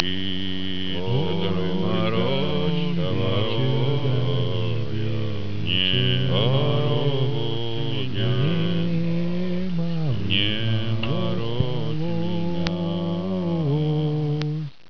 2. Three national russian songs "Troyka", "Moroz - moroz" and "Oy da ne vecher", coming from three different directions (0o, 30o, 70o) on the background of a light undirected wite noise would be listened as
a song mixture.
3songs.wav